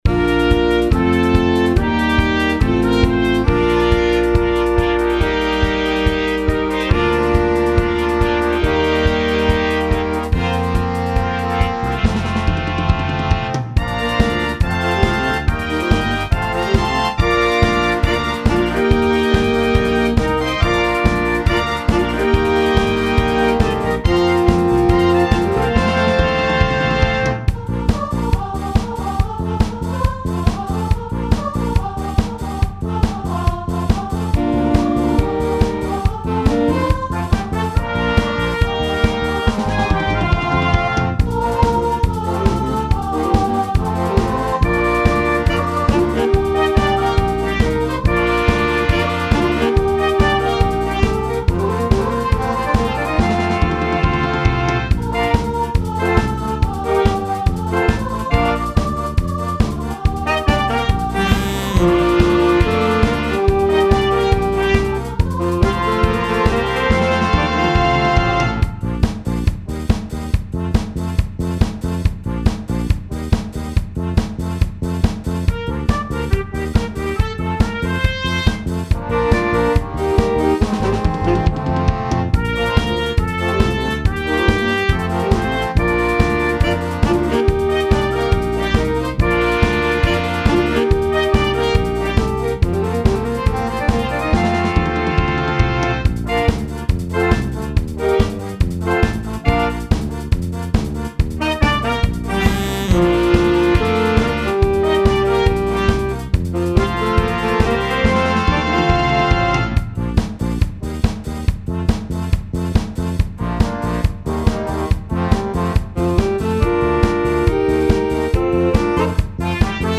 Blasmusik Schlagwörter
Gesang , Tanzlieder (SK-CZ) Share On